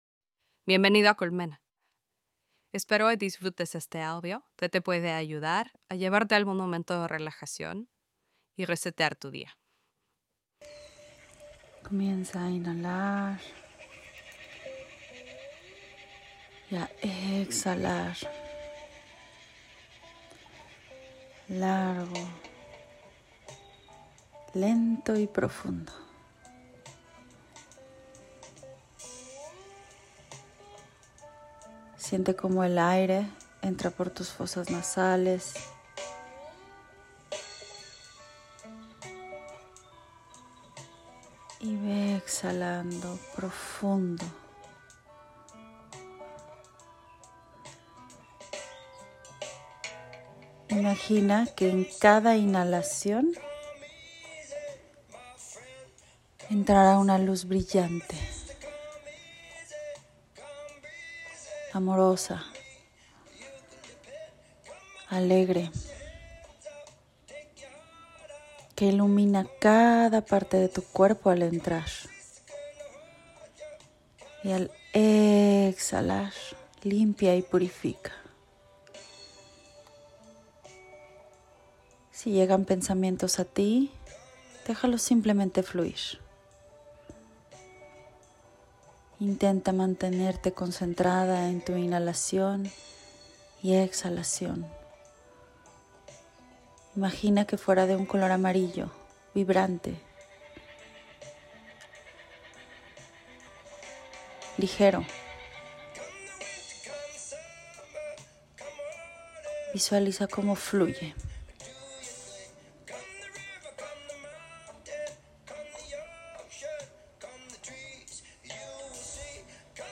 ElevenLabs_Viaje_a_mi_sabiduria.mp3